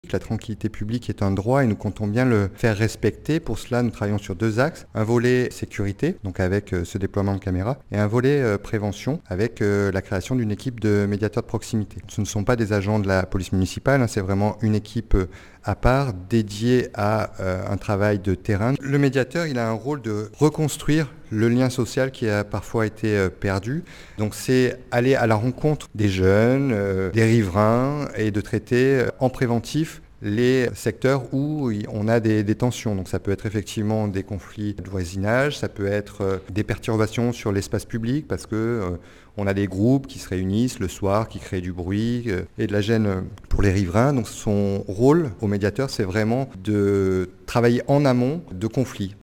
Simon Plénet, maire d’Annonay